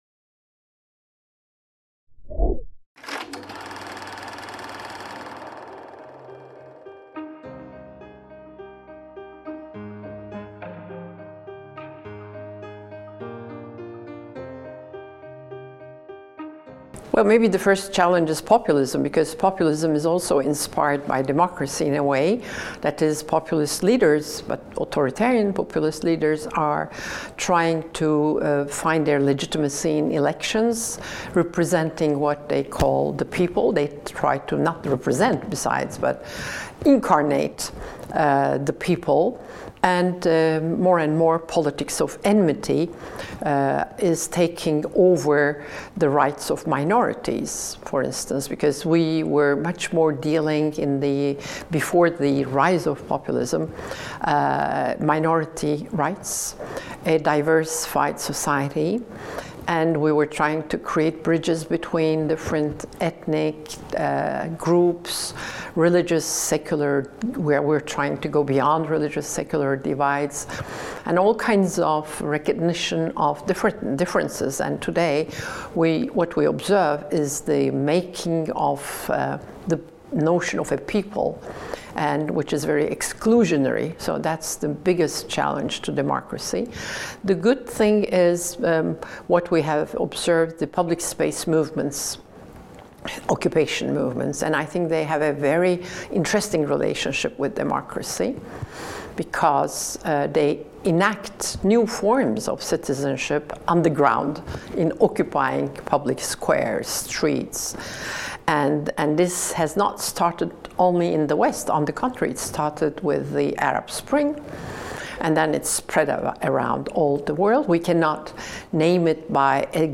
Nilüfer Göle's interview | Canal U
Nilüfer Göle's interview about the fragility of democracy